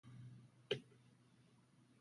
silenzio.wav